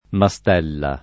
mastello [ ma S t $ llo ] s. m.